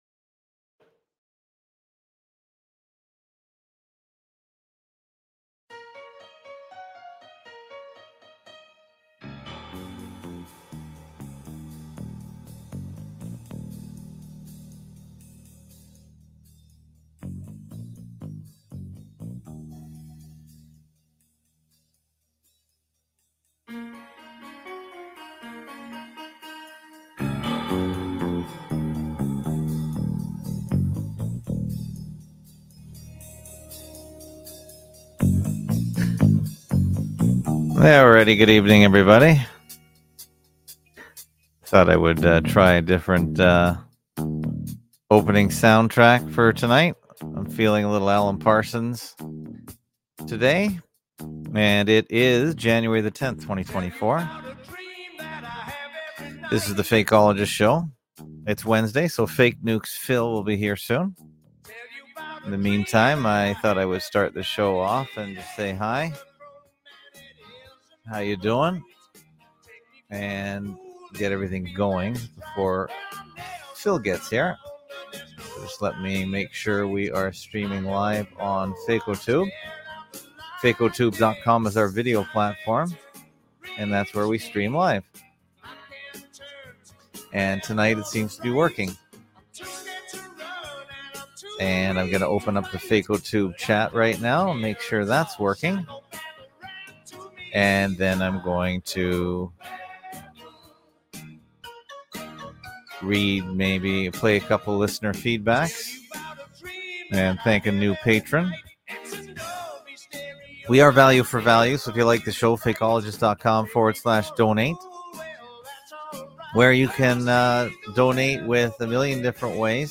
Live Stream